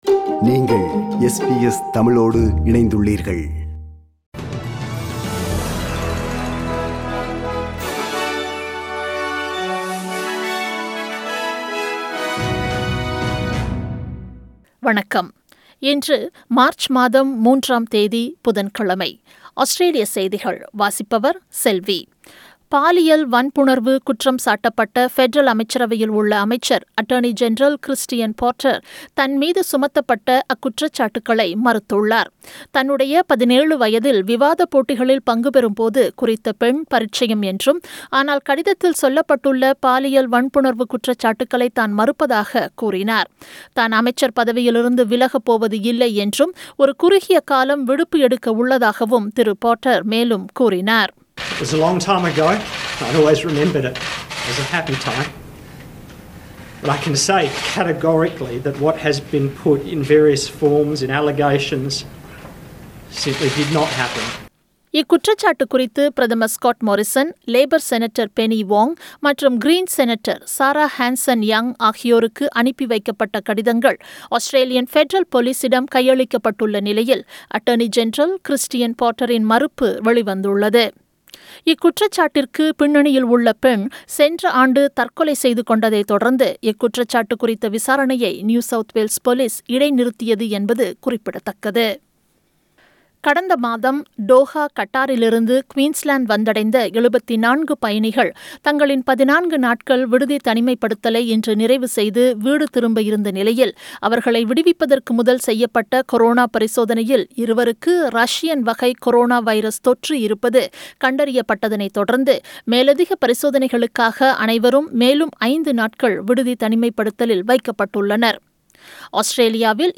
Australian news bulletin for Wednesday 03 March 2021.